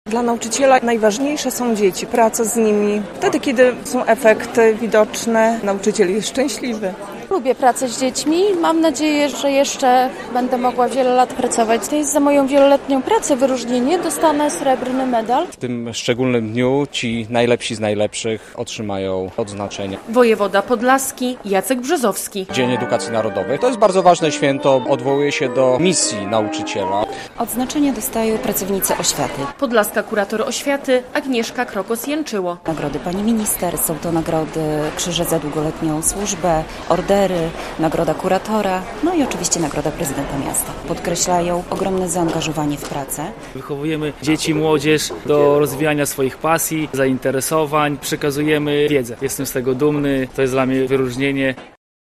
Odznaczenia państwowe i nagrody dla nauczycieli na obchodach Dnia Edukacji Narodowej- relacja
Uroczystość odbyła się w budynku Opery i Filharmonii Podlaskiej w Białymstoku.